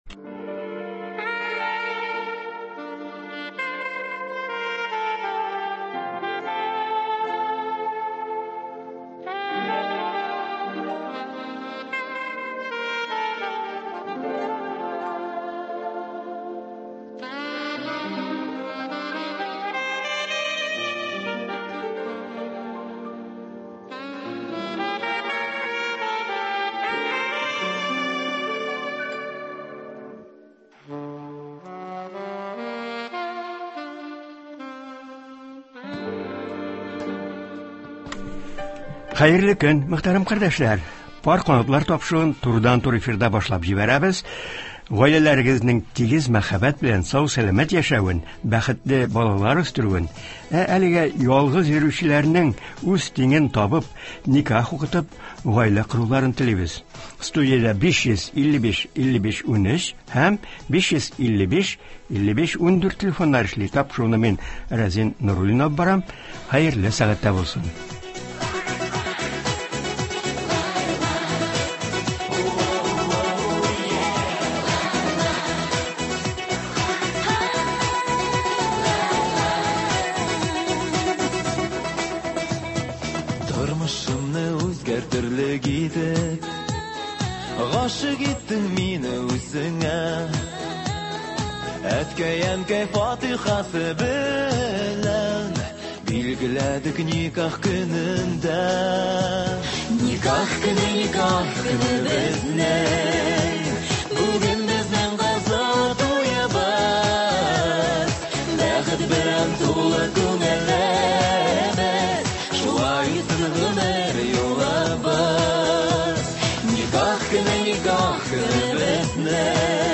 турыдан-туры эфирда
тыңлаучылардан килгән сорауларга җавап бирәчәк.